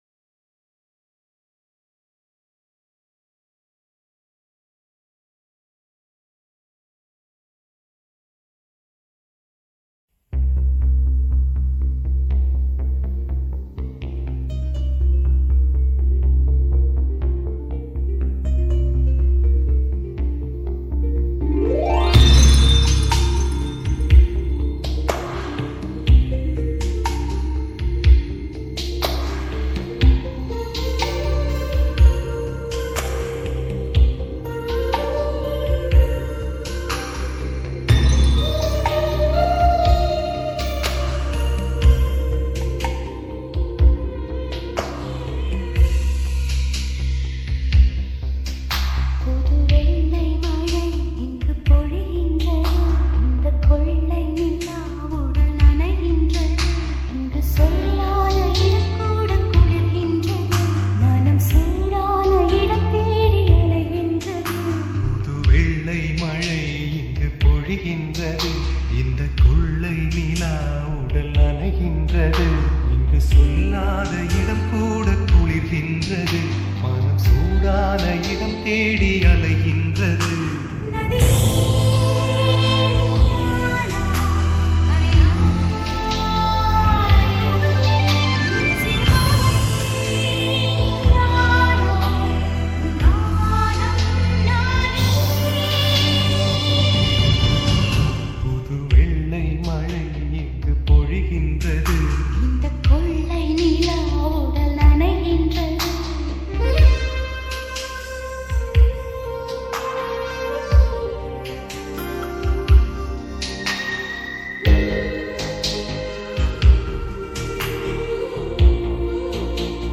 ALL TAMIL DJ REMIX
Tamil 8D Songs